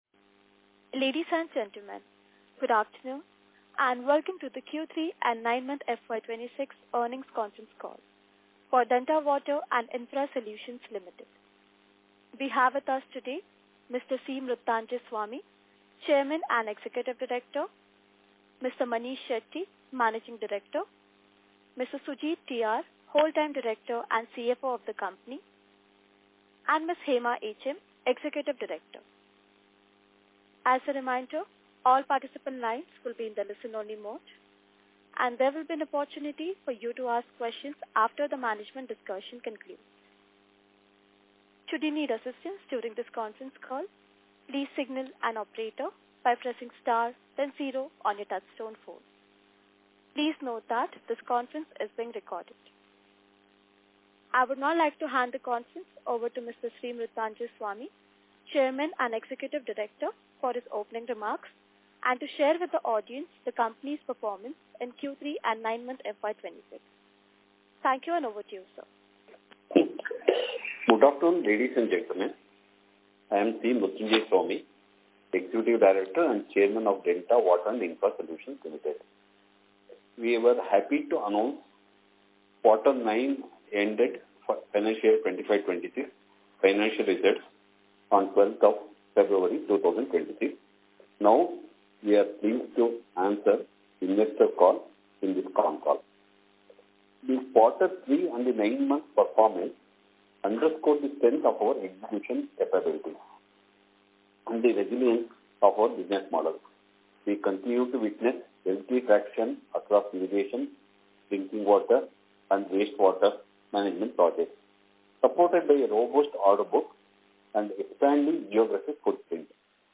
Q3 & 9M – FY26 Results Earnings Call